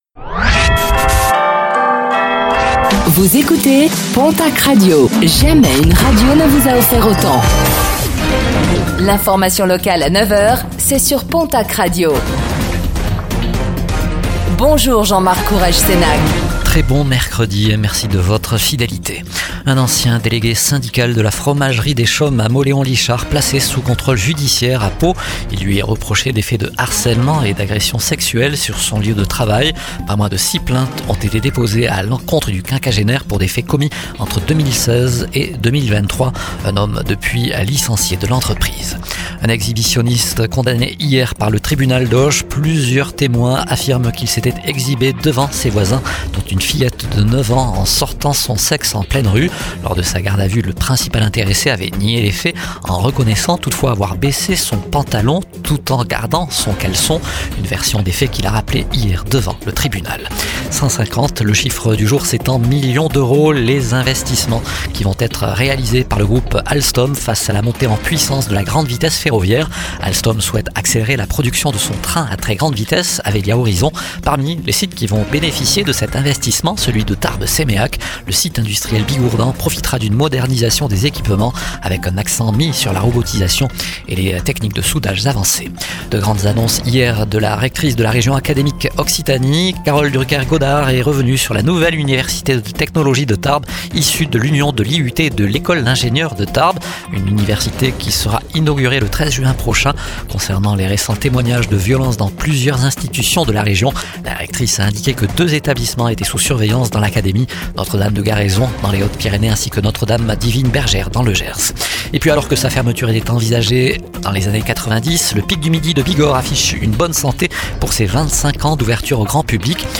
Infos | Mercredi 09 avril 2025